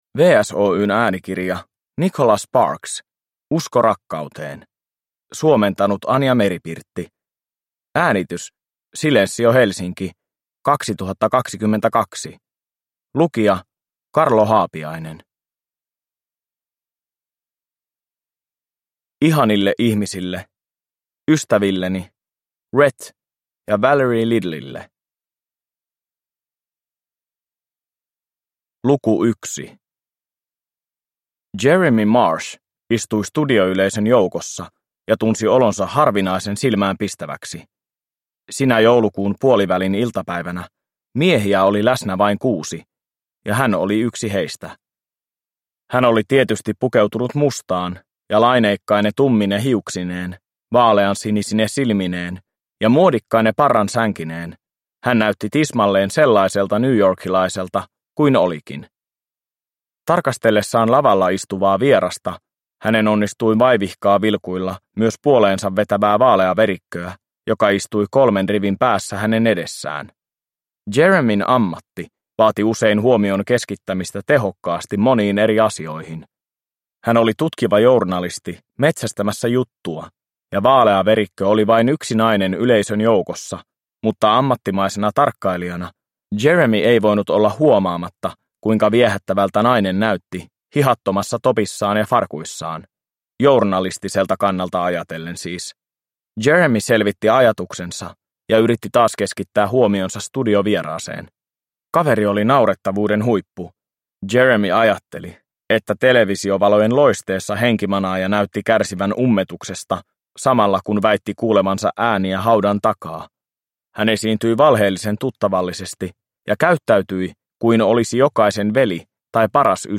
Usko rakkauteen – Ljudbok – Laddas ner